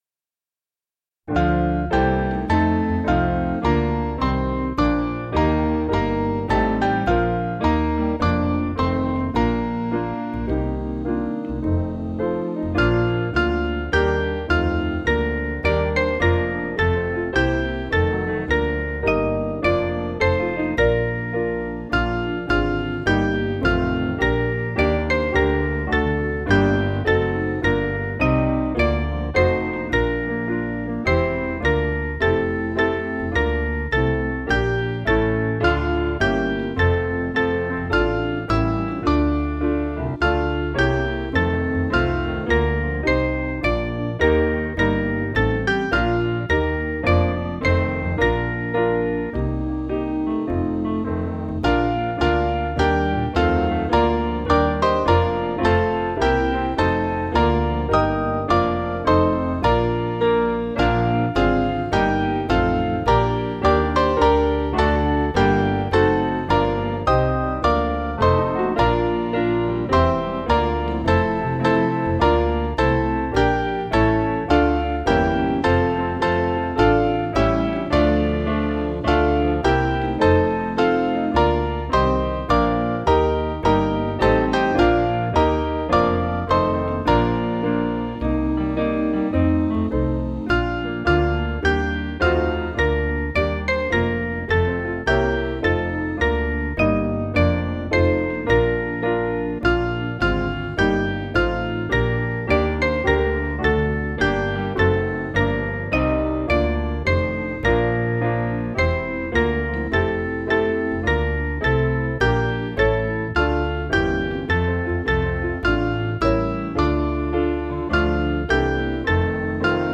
Mainly Piano
(CM)   4/Bb-B